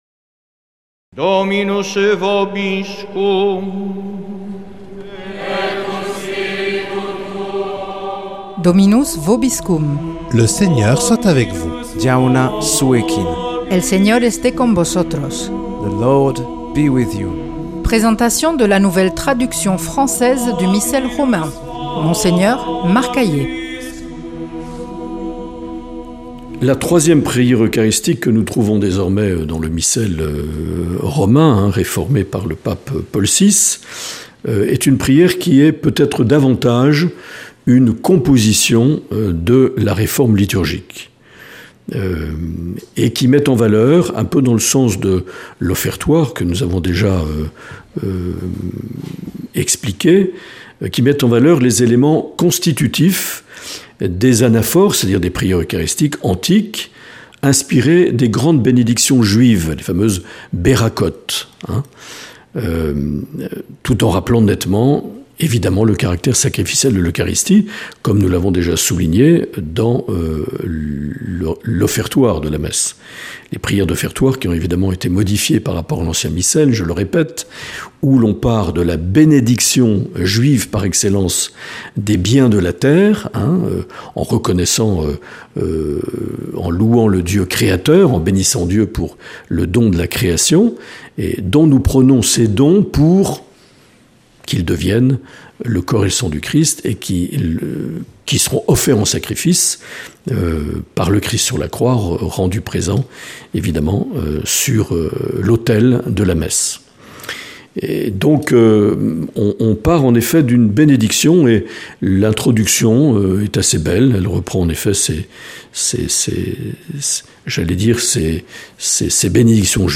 Présentation de la nouvelle traduction française du Missel Romain par Mgr Marc Aillet